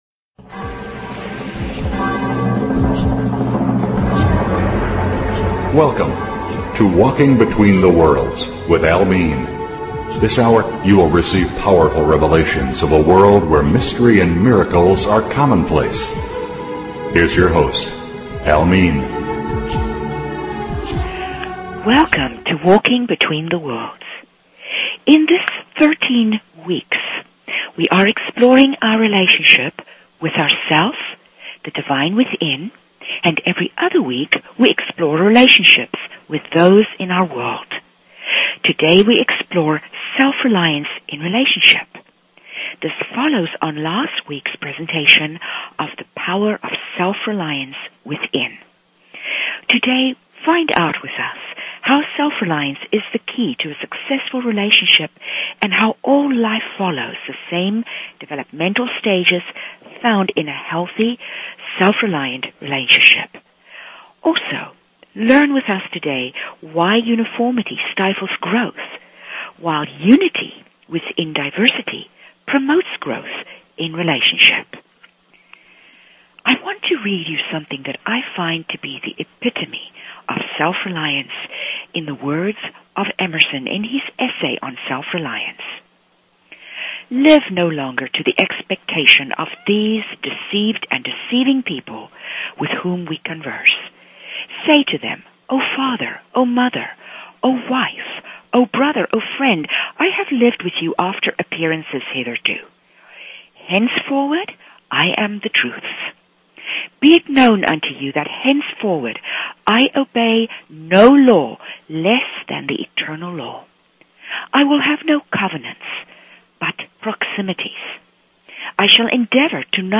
Talk Show Episode, Audio Podcast, Secrets_of_the_Hidden_Realms and Courtesy of BBS Radio on , show guests , about , categorized as
These interviews are powerful enough to change your life!